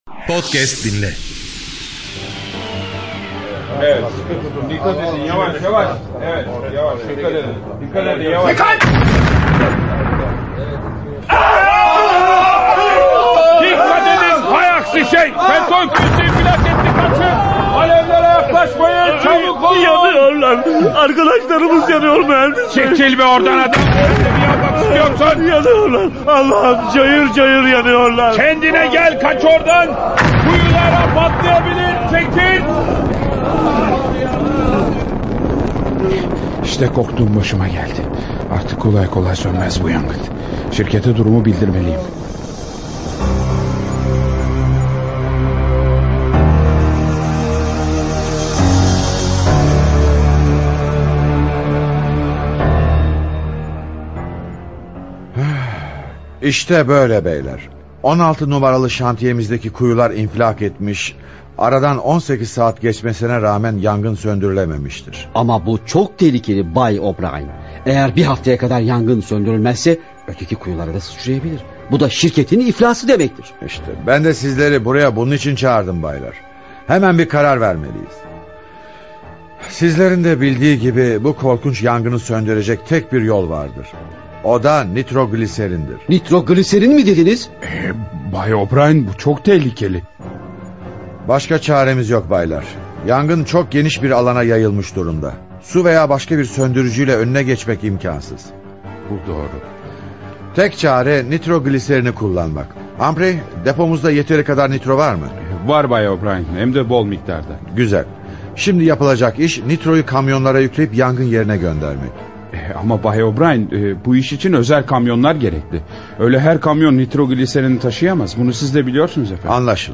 Radyo Tiyatrosu